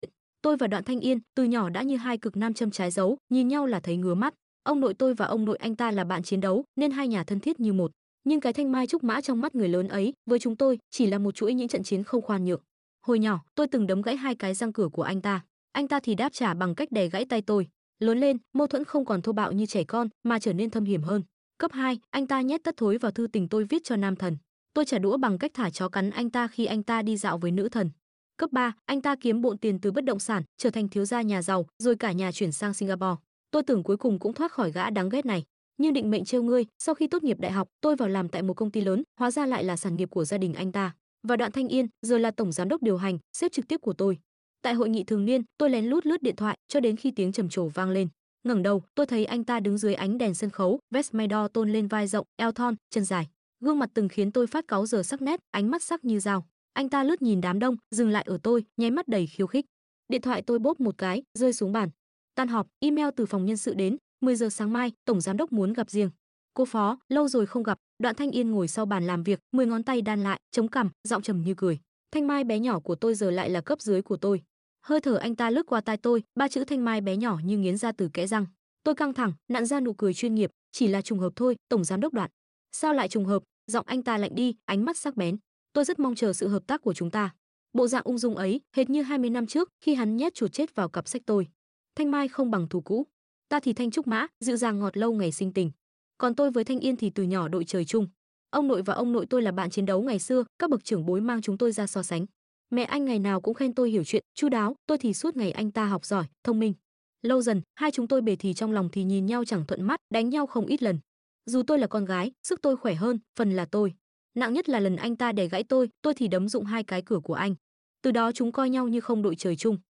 TRUYỆN AUDIO|| HÀNH TRÌNH TỪ sound effects free download